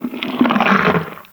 MONSTER_Slime_03_mono.wav